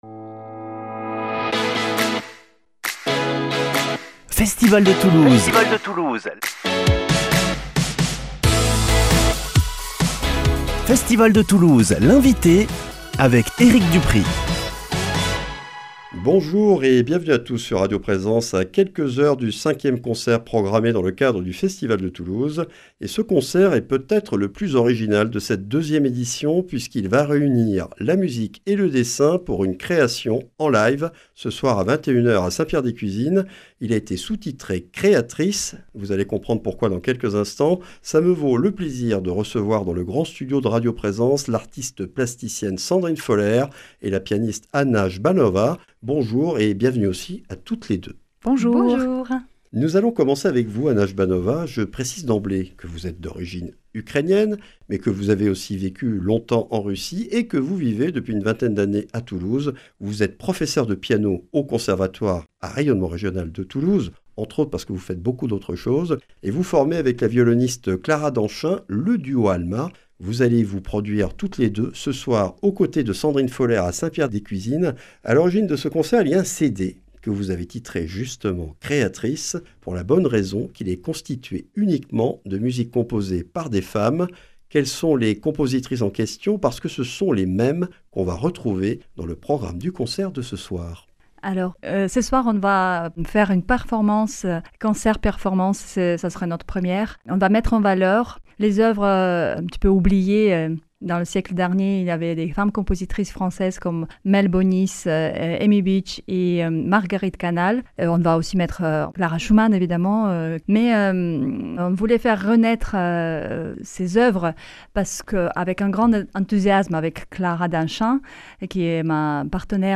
Festival de Toulouse - Interviews